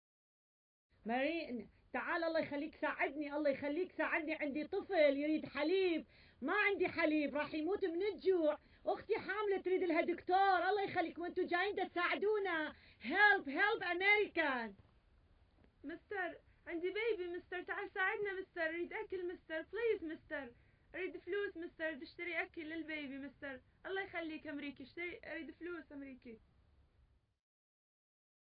Звук женского голоса на арабском с ломанным английским